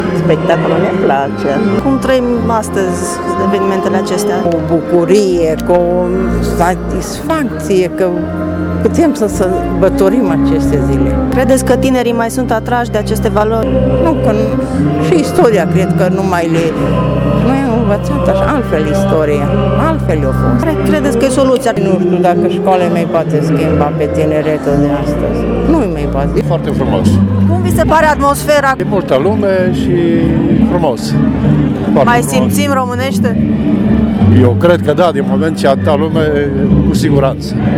Târgumureșenii au fost bucuroși că au avut ocazia să asculte cântece patriotice de la unii dintre cei mai mari artiști români chiar dacă, spun ei, patriotismul este o noțiune întâlnită mai degrabă la oamenii în vârstă: